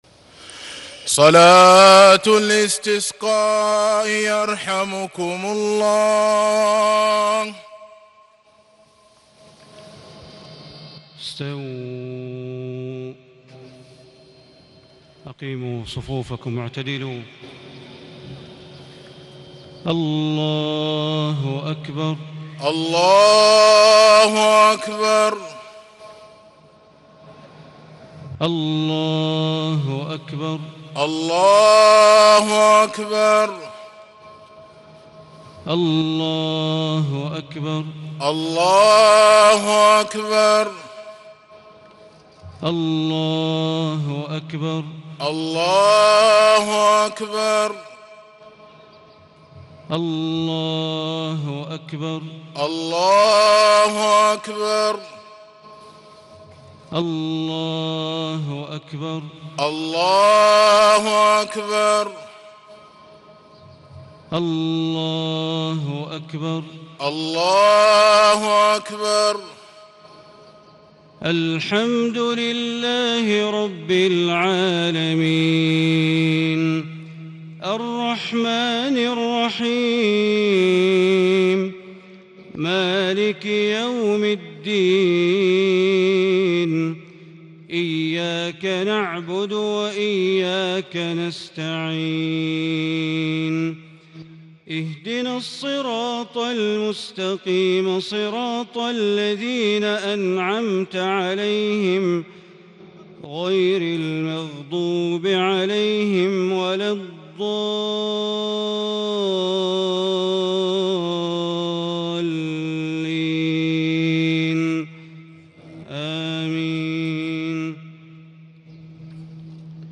صلاة الاستسقاء ليوم الخميس 10 صفر 1438 هـ للشيخ د. بندر بليلة تلا سورتي الأعلى والغاشية > صلاة الأستسقاء > المزيد - تلاوات بندر بليلة